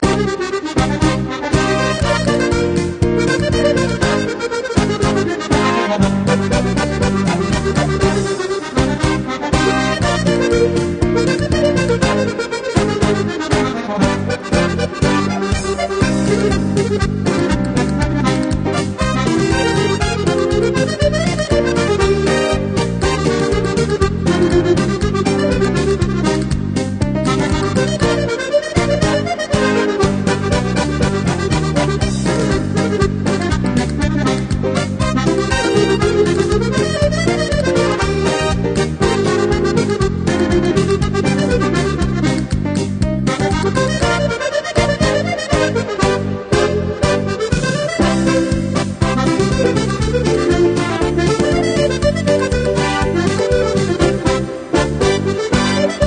Titres enchain?s pour danser